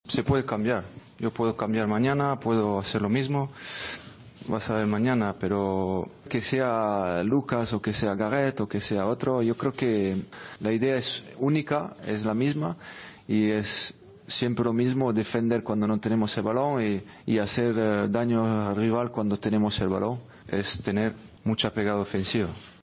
El técnico del Real Madrid habló en la previa del encuentro de vuelta de los octavos de final de la Champions frente a la Roma: "Lo único que tenemos que hacer es ganar el partido porque ilusiona, pero tenemos que salir pensando únicamente en el partido de mañana".